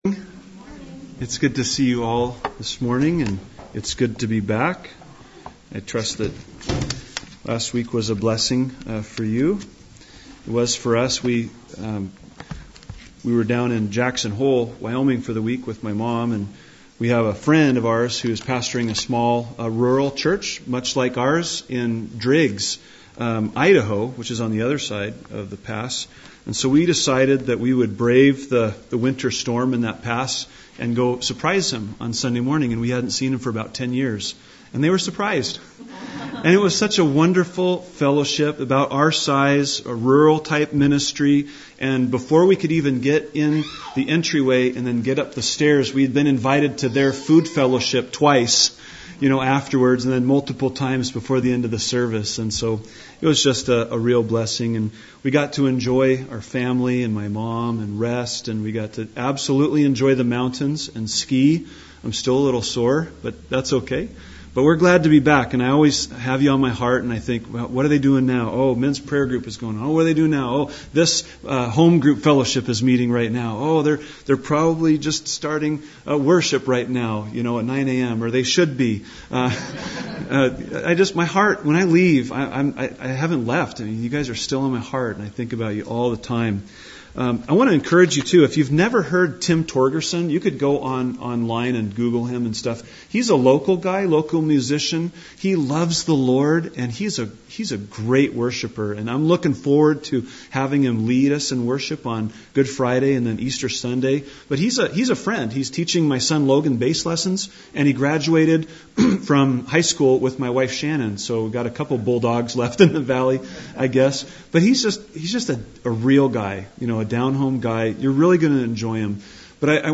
A message from the series "Gospel of John."